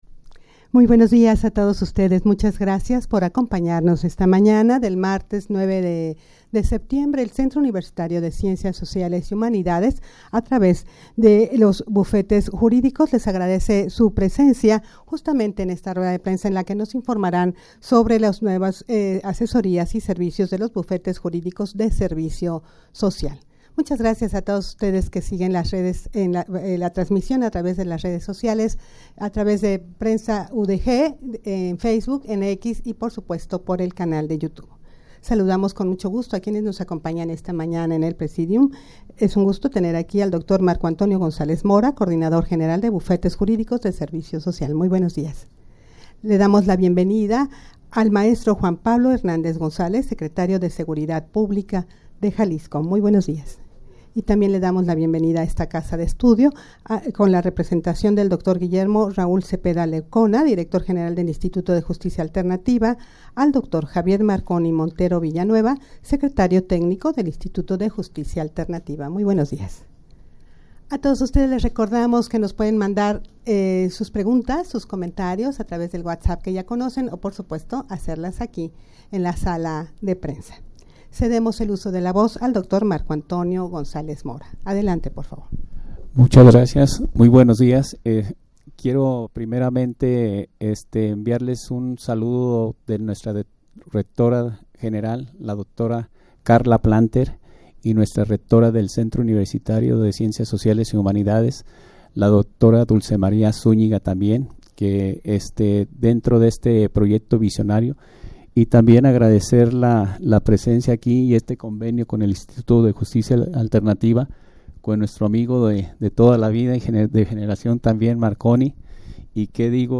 rueda-de-prensa-para-informar-sobre-las-asesorias-de-los-bufetes-juridicos-de-servicio-social.mp3